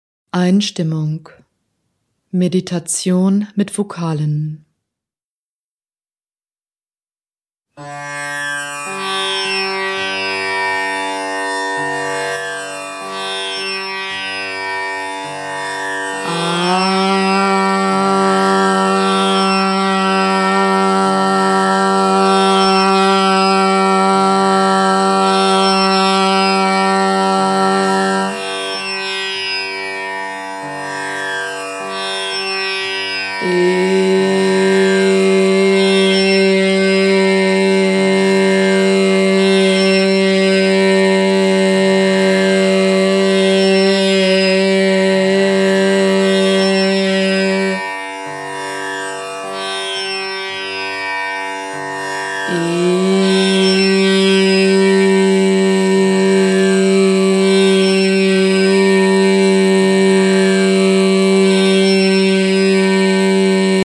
Kapitel Sanskrit und Deutsch mit Tampura und Harmonium